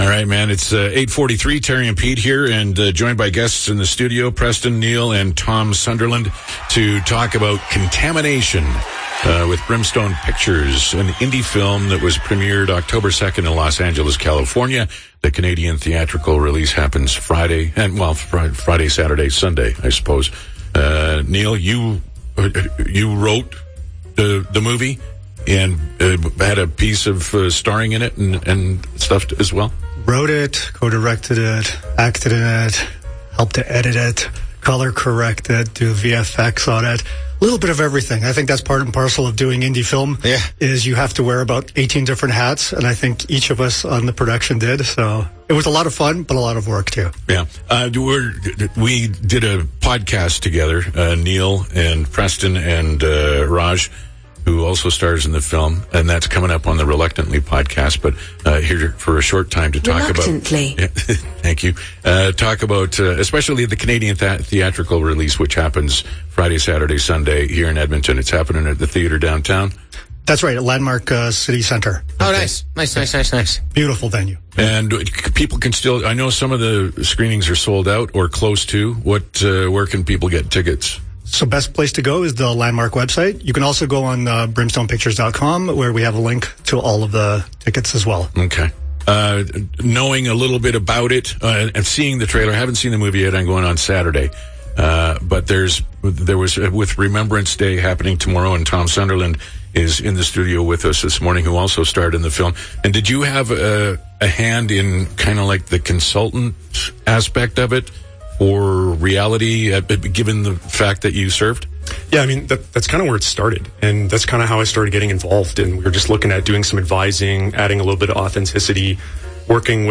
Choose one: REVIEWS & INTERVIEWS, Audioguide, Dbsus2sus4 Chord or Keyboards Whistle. REVIEWS & INTERVIEWS